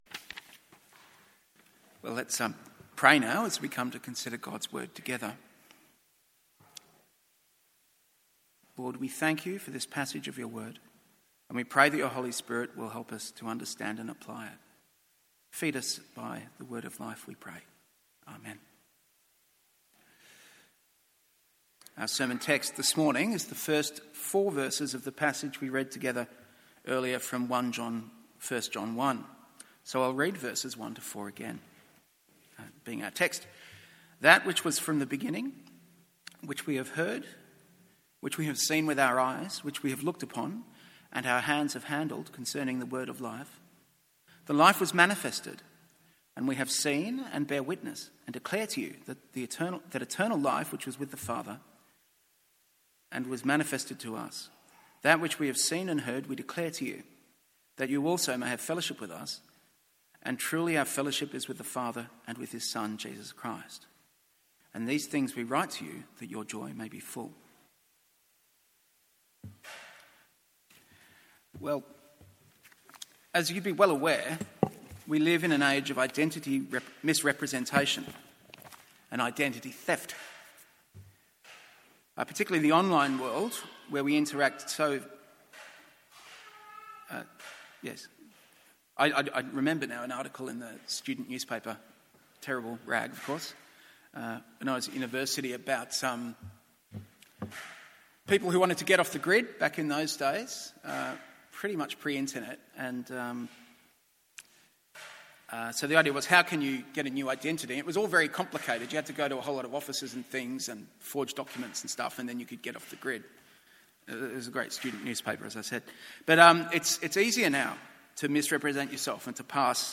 MORNING SERVICE Isa 59:1-21; 1 John 1:1-10…